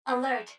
153_Alert.wav